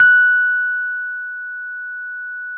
RHODES CL0KL.wav